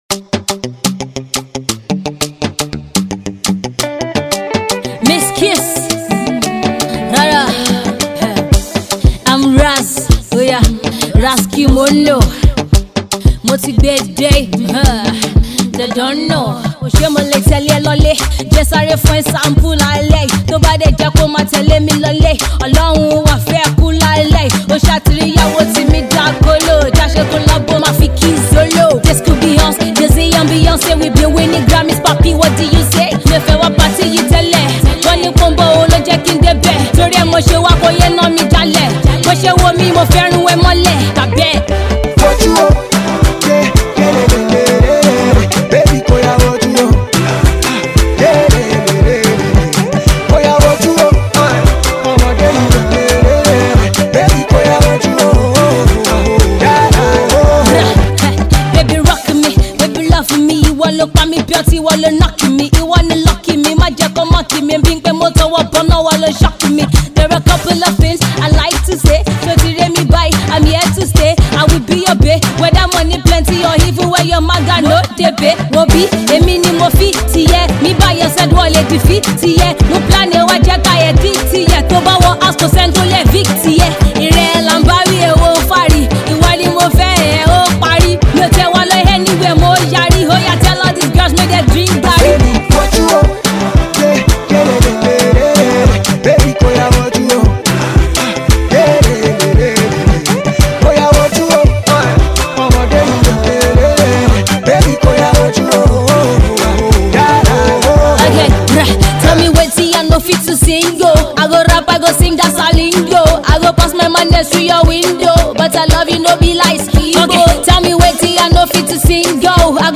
Indigenous Pop, Rap …